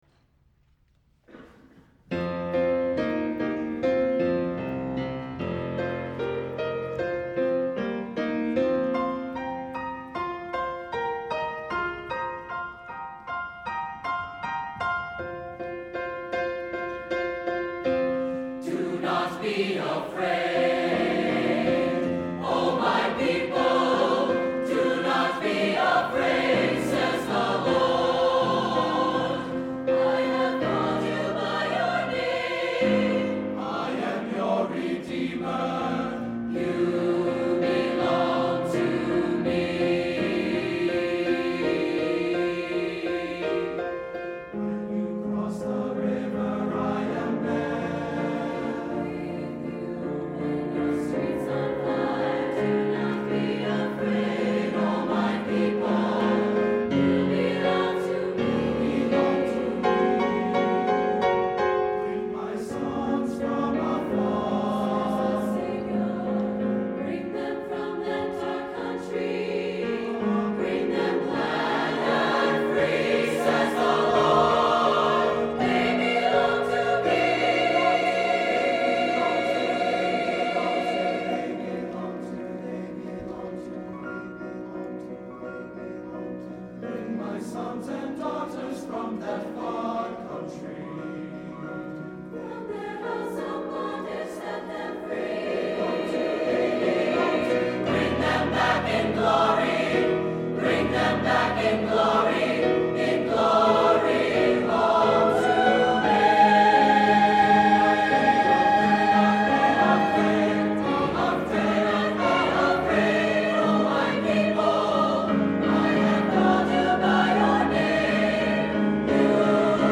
for SATB Chorus and Piano (2006)